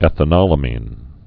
(ĕthə-nŏlə-mēn, -nōlə-)